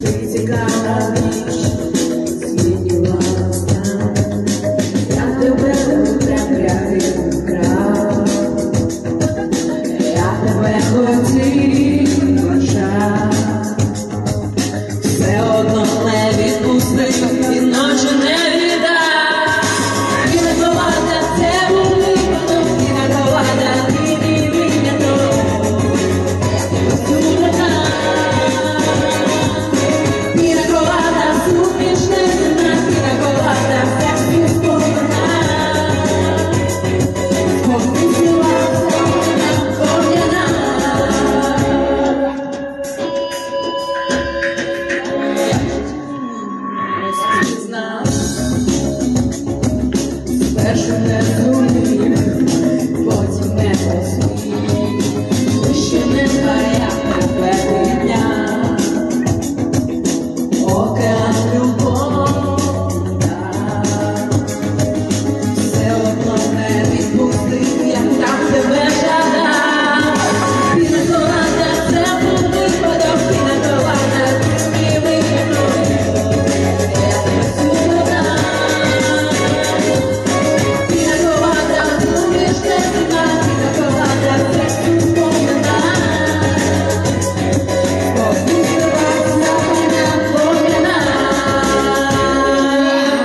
Караоке вечори в Одесі